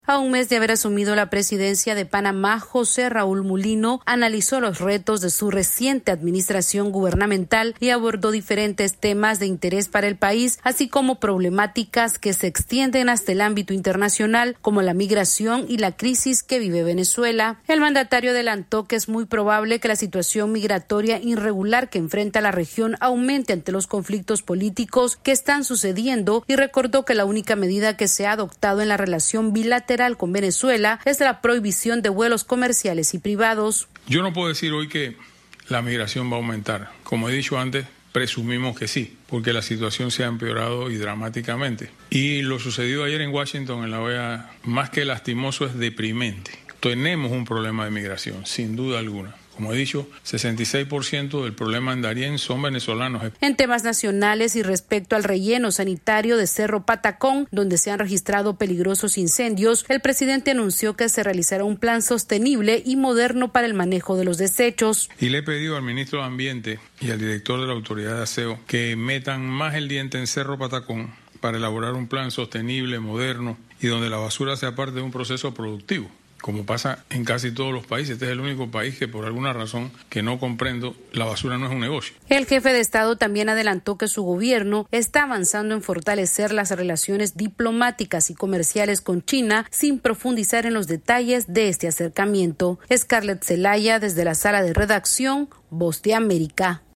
AudioNoticias
El presidente de Panamá, José Raúl Mulino, cumplió un mes como presidente del país canalero y durante un conversatorio analizó los retos que enfrenta su gobierno, entre ellos la migración irregular por el Darién. Esta es una actualización de nuestra Sala de Redacción...